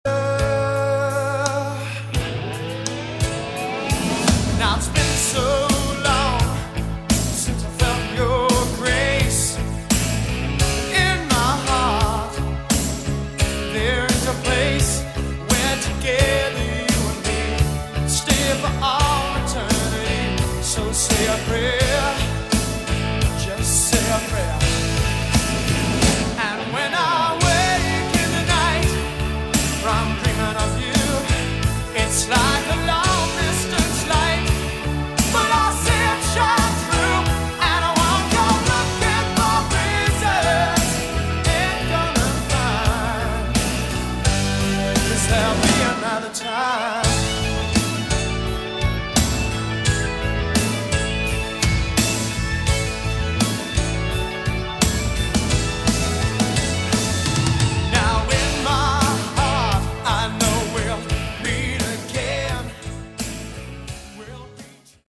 Category: AOR
vocals
bass
drums
keyboards